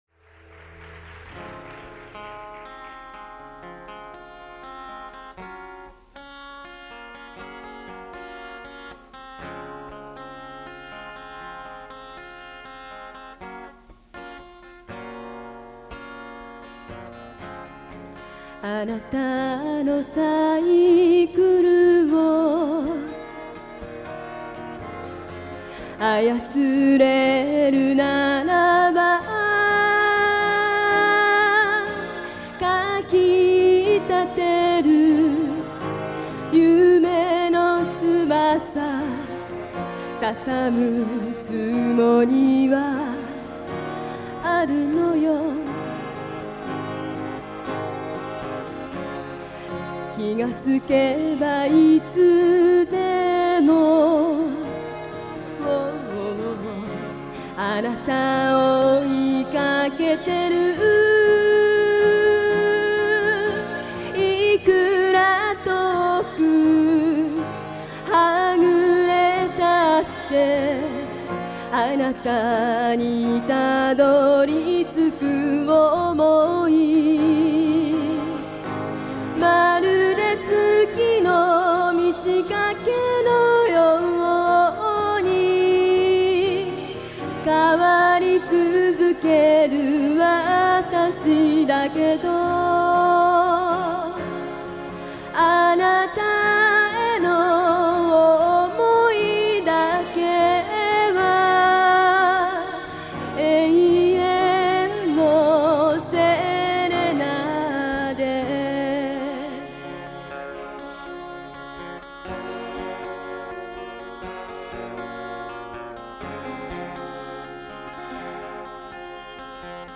1985年9月22日に行われた記念コンサート